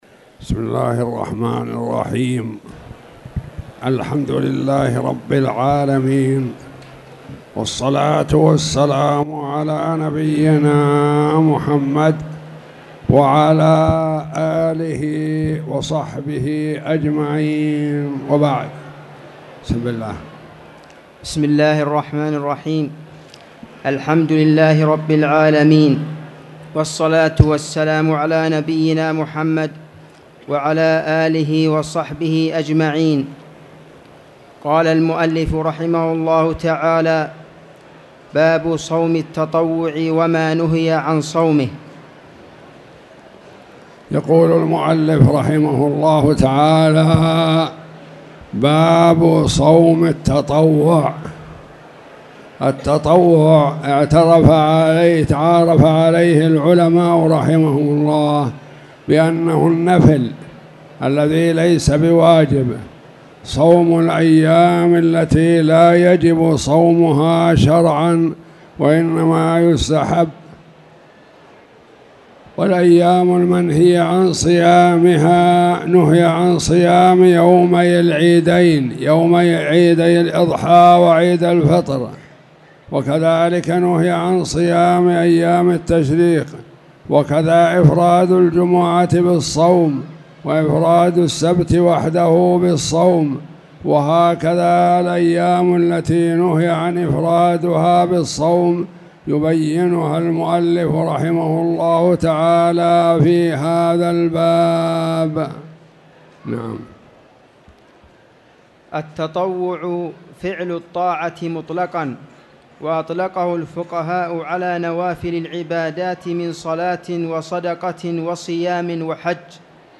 تاريخ النشر ٧ شوال ١٤٣٧ هـ المكان: المسجد الحرام الشيخ